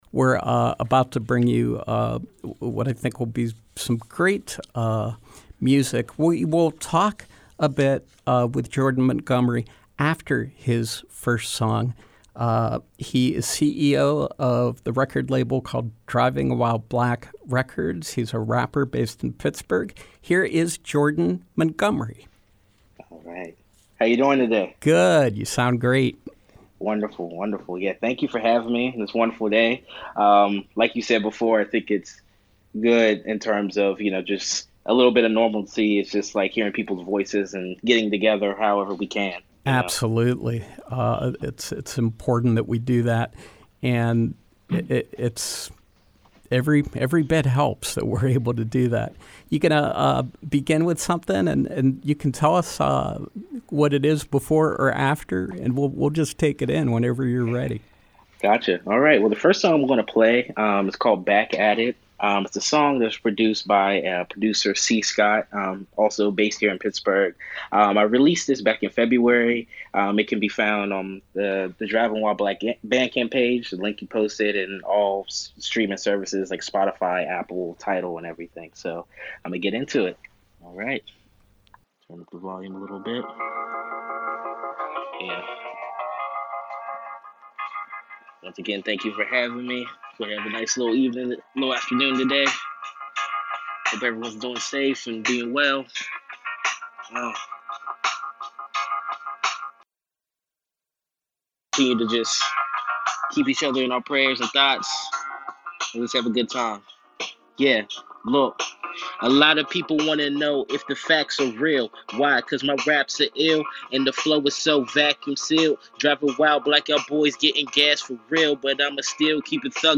Live performance and interview
Pittsburgh-based rapper